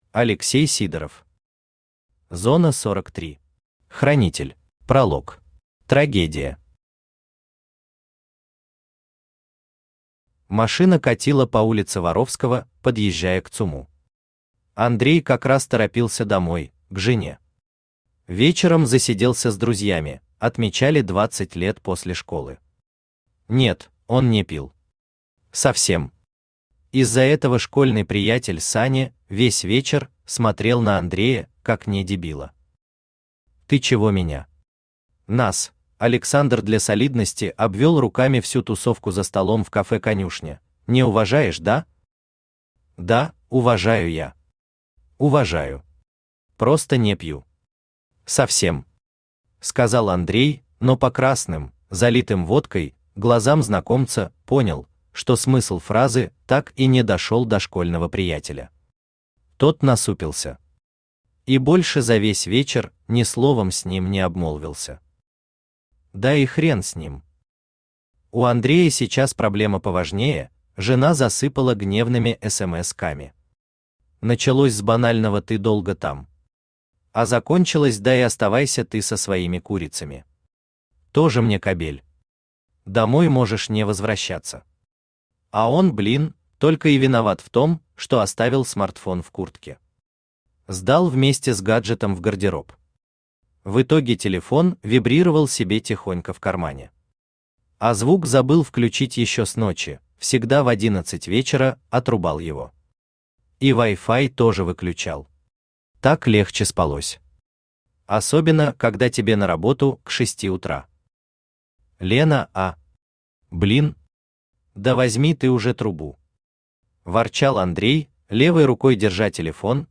Аудиокнига Зона-43. Хранитель | Библиотека аудиокниг
Хранитель Автор Алексей Вениаминович Сидоров Читает аудиокнигу Авточтец ЛитРес.